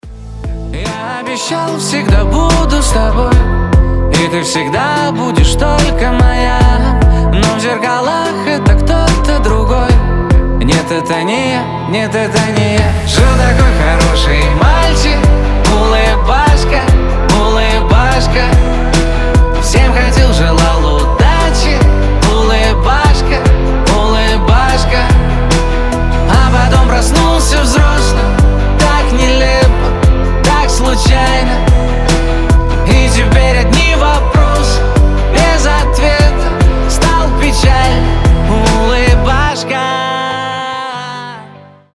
поп , грустные , мужской голос